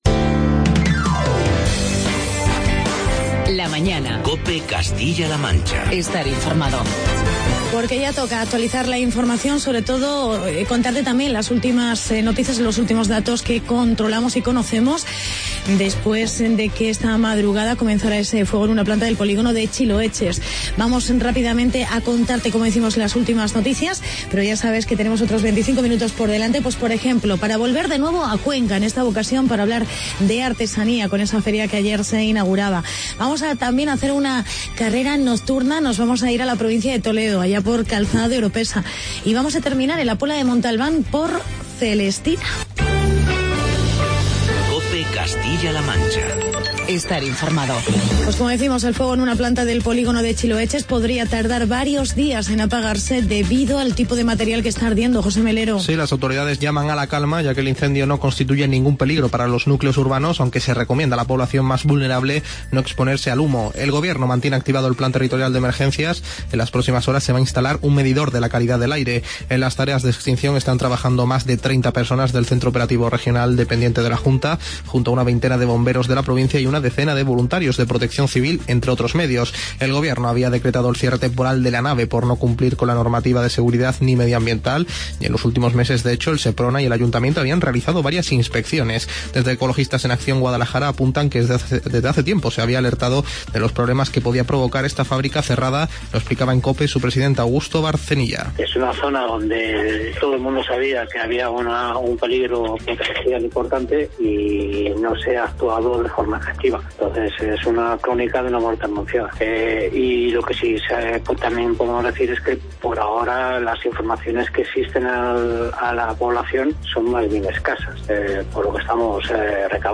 Entrevista con el alcalde de Calzada de Oropesa sobre la carrera noctura.